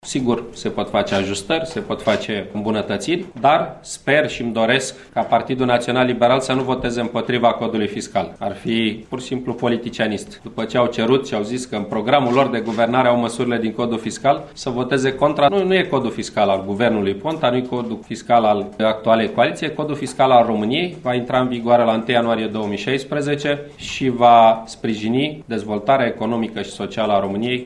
Înainte de şedinţa de plen, prevăzută să înceapă la ora 16,00, premierul Victor Ponta şi-a exprimat speranţa ca toate partidele parlamentare să susţină cele două proiecte :